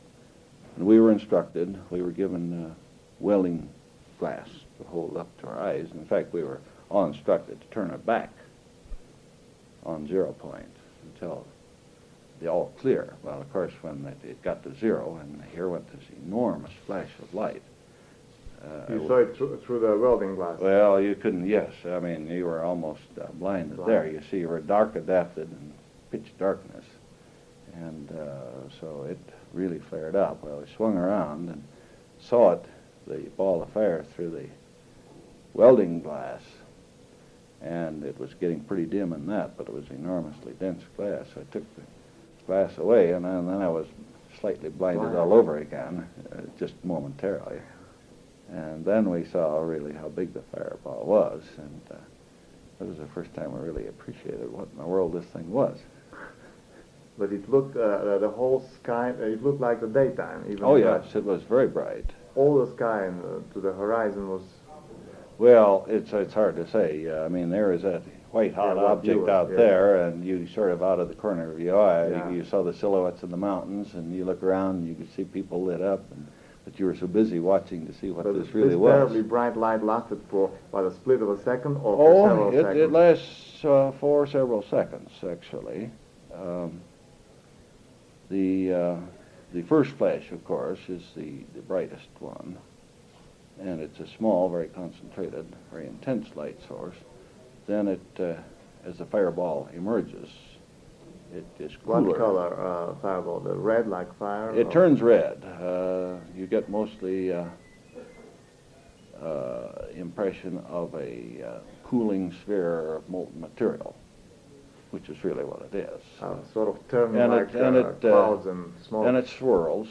In our search for understanding the role of sound in life with the bomb, we must turn to another form of aural record - that of eyewitness accounts. Here are a few examples, excerpted from longer oral histories: